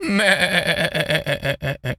goat_baa_calm_06.wav